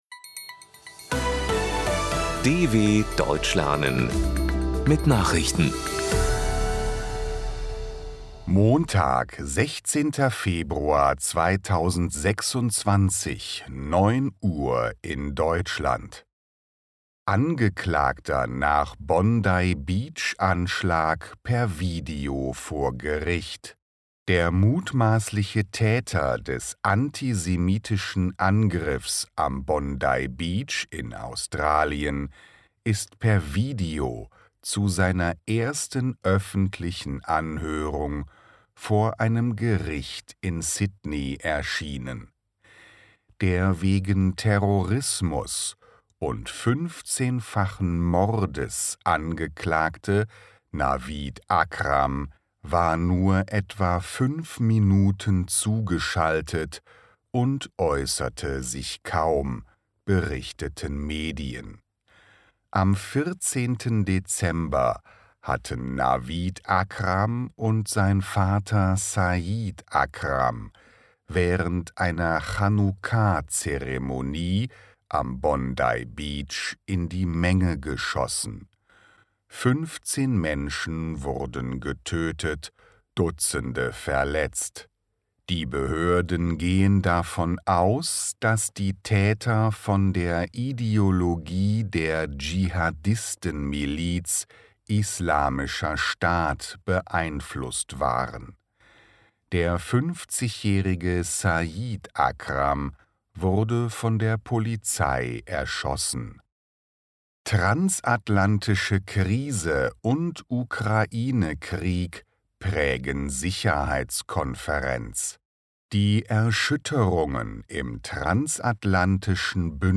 16.02.2026 – Langsam Gesprochene Nachrichten
Trainiere dein Hörverstehen mit den Nachrichten der DW von Montag – als Text und als verständlich gesprochene Audio-Datei.